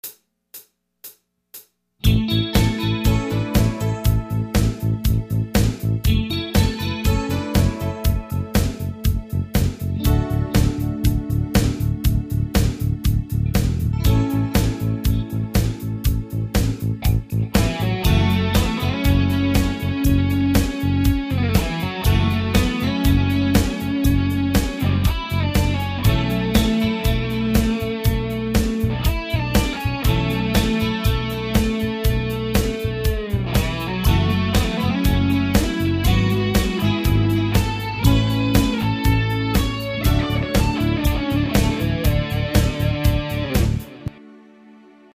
Los Angeles based guitarist